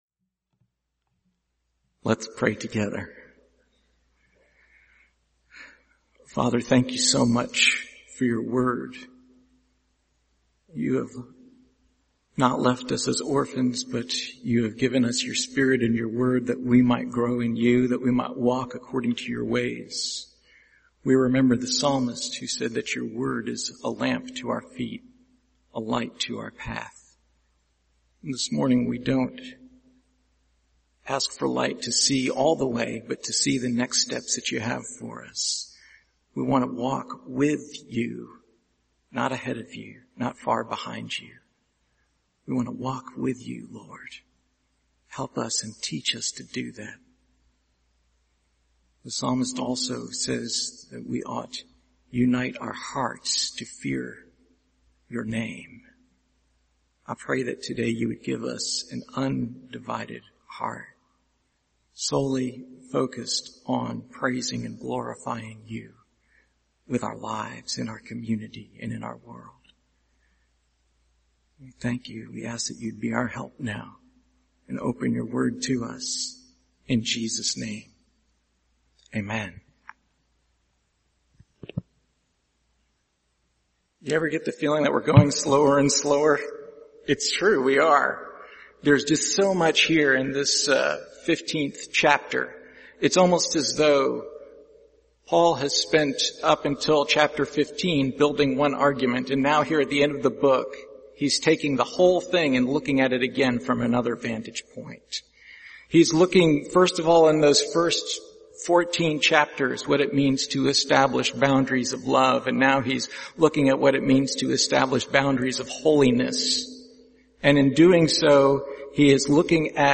1 Corinthians Passage: 1 Corinthians 15:29-35 Service Type: Sunday Morning Share this